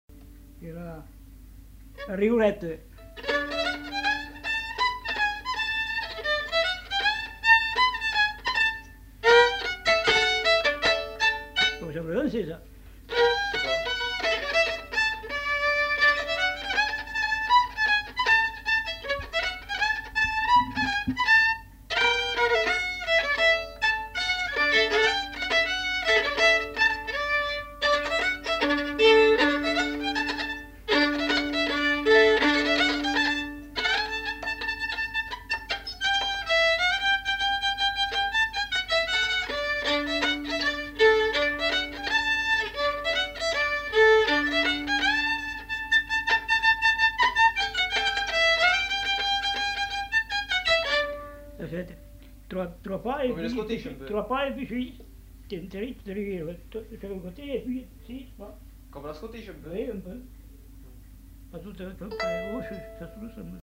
Aire culturelle : Lomagne
Genre : morceau instrumental
Instrument de musique : violon
Danse : scottish
Notes consultables : Suivi d'un autre thème.